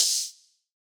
Open Hats